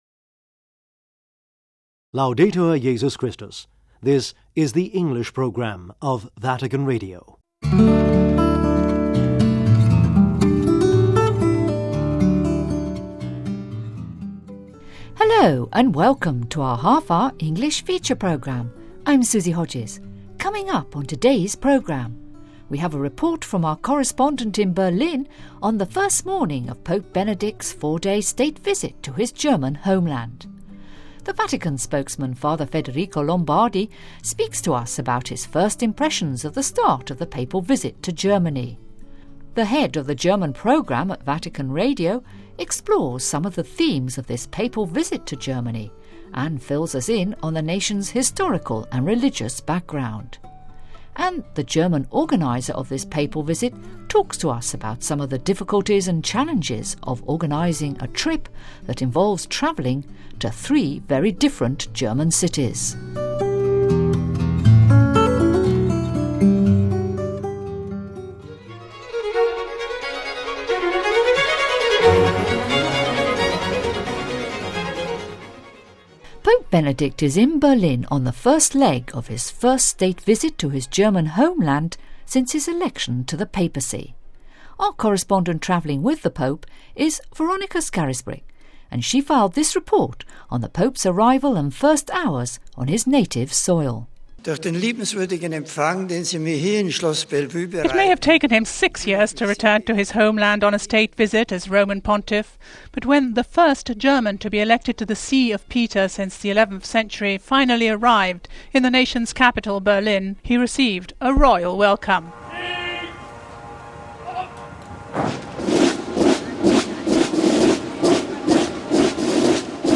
We have a report from our correspondent in Berlin on the first morning of Pope Benedict's 4-day state visit to his native Germany....